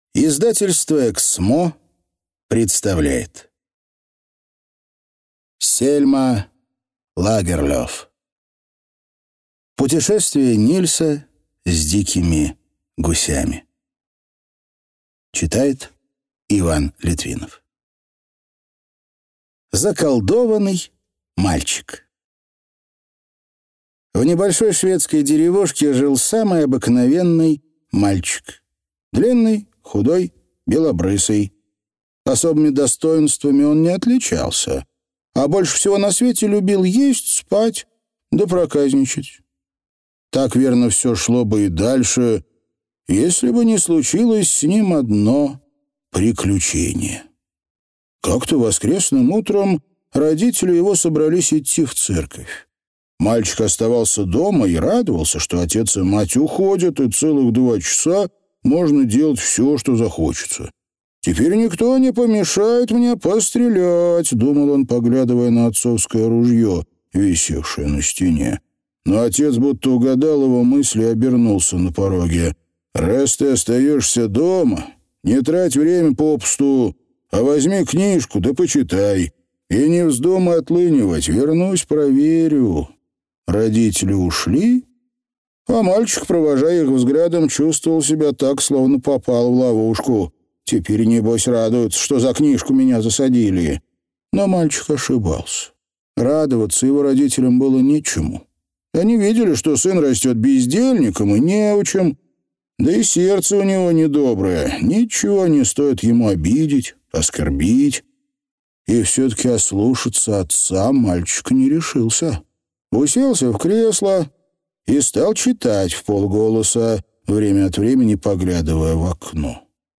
Аудиокнига Путешествие Нильса с дикими гусями | Библиотека аудиокниг